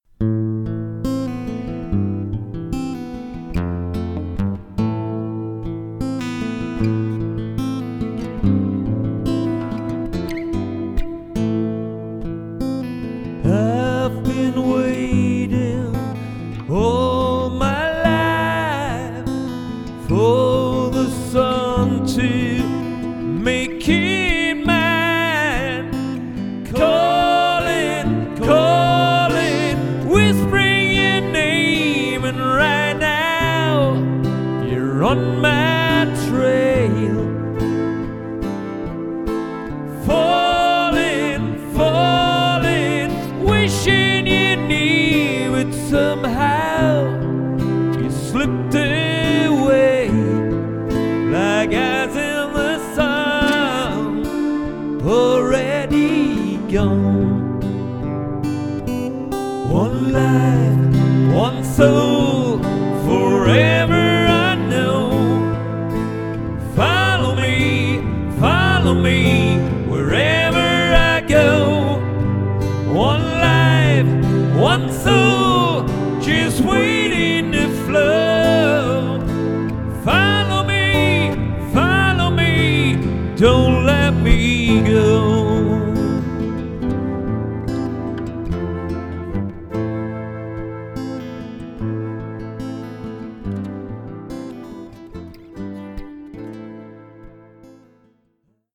• Rockband
• Coverband